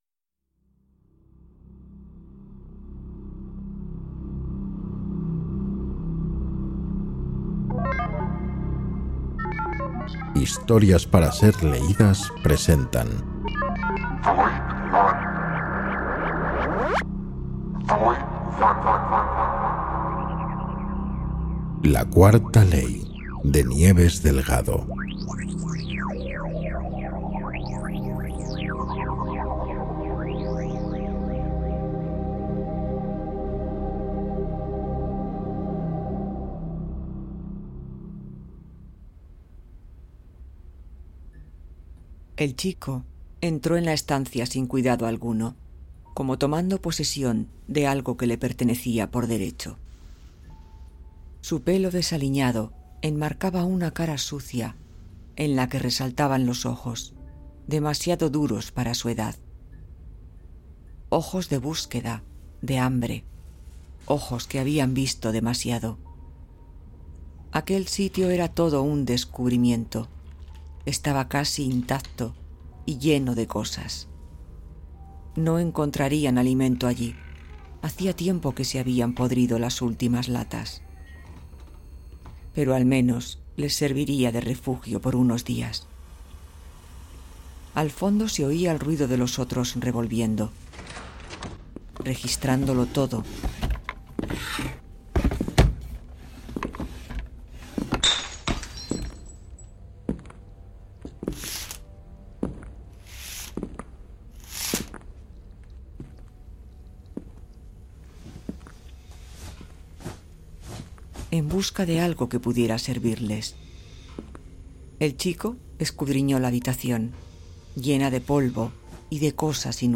Audiolibro de ciencia ficción (voz humana)